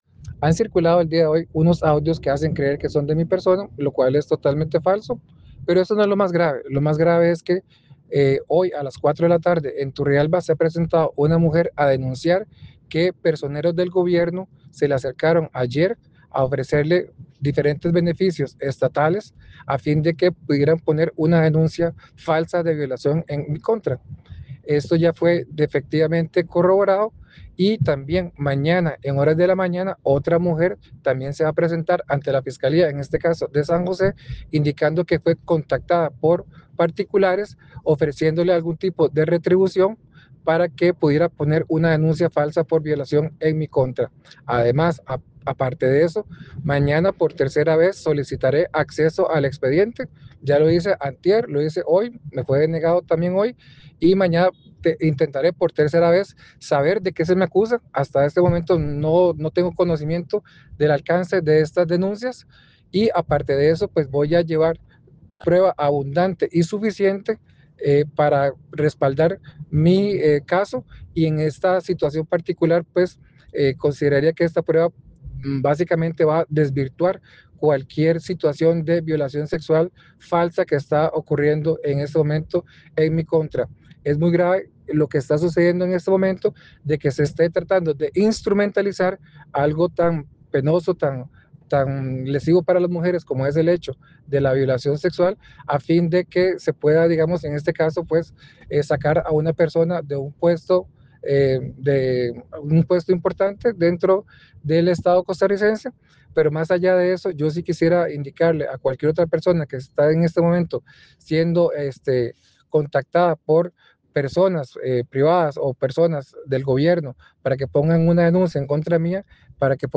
“Es muy grave lo que está sucediendo en este momento. Se está tratando de instrumentalizar algo tan penoso y lesivo para las mujeres como es la violación sexual, con el objetivo de sacar a una persona de un puesto importante dentro del Estado costarricense”, manifestó Zúñiga en un audio compartido con medios de comunicación.